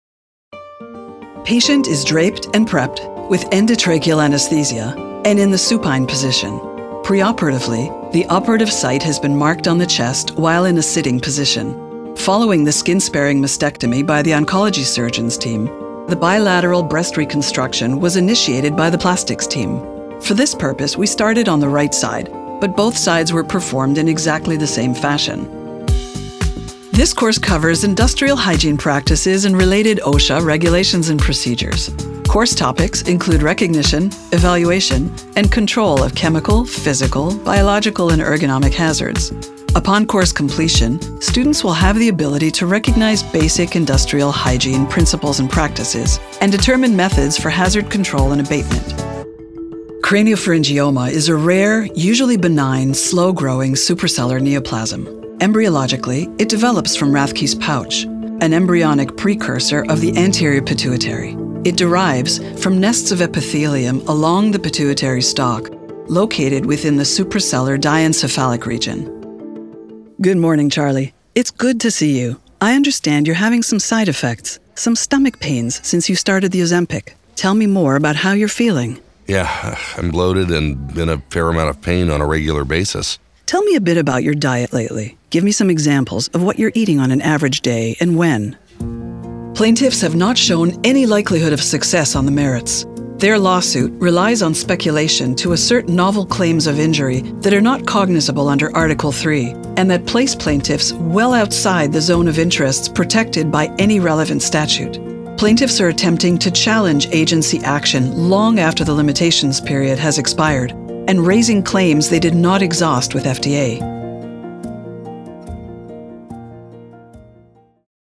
Specializing in peer to peer medical voiceover.
Montreal English / Canadian English
Velvety smooth, luxurious contralto.
Paired with a delivery that is professional and authoritative, the Doctor’s voice is sophisticated, clear, smooth and articulate.